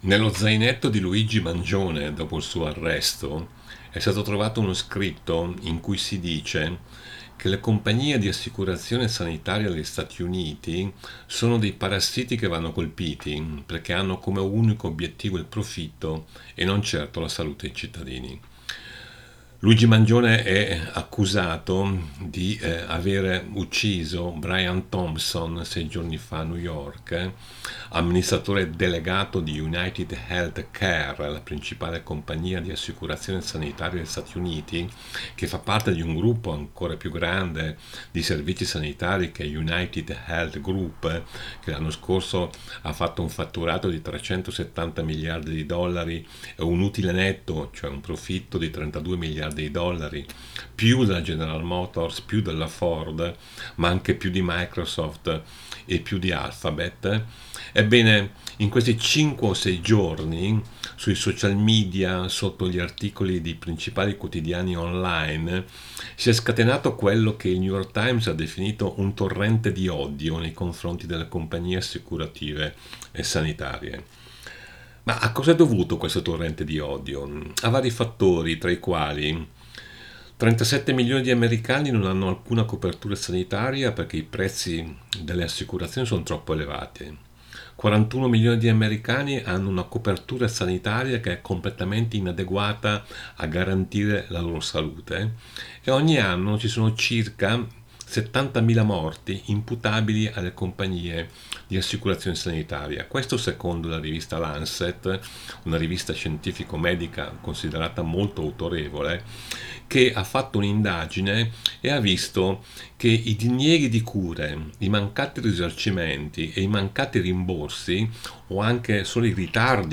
Un commento dal nostro corrispondente dalla East Coast